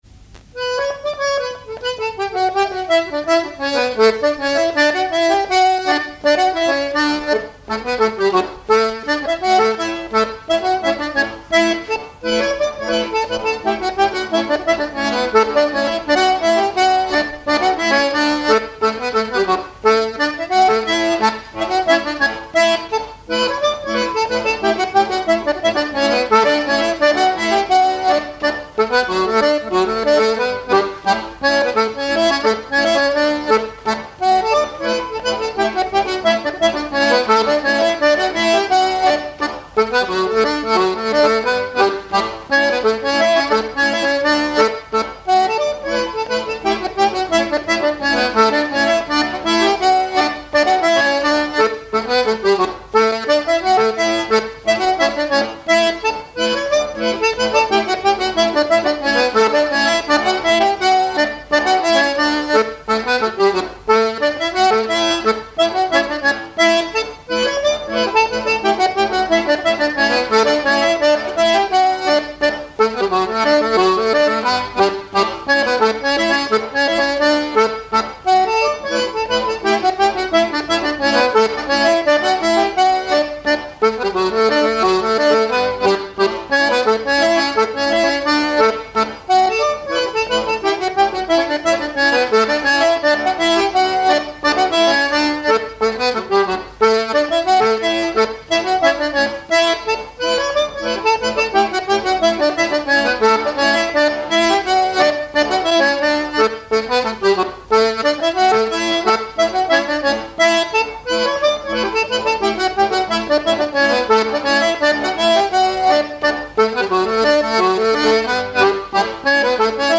danse : pas d'été
airs de danse à l'accordéon diatonique
Pièce musicale inédite